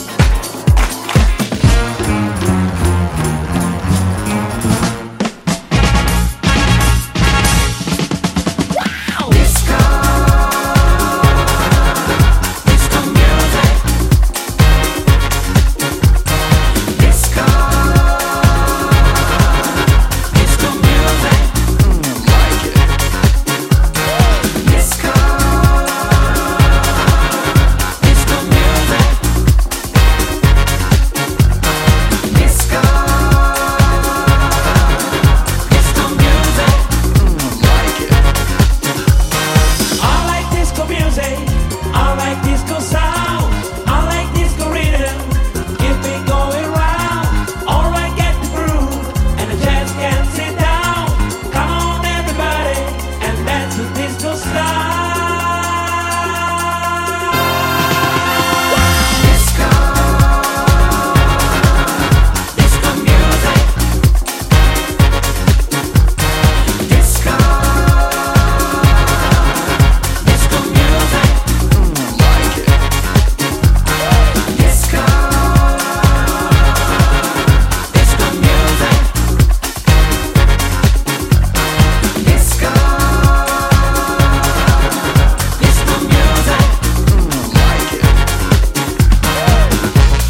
感動的なストリングス等々を交え展開していくディスコハウスに仕上がっています！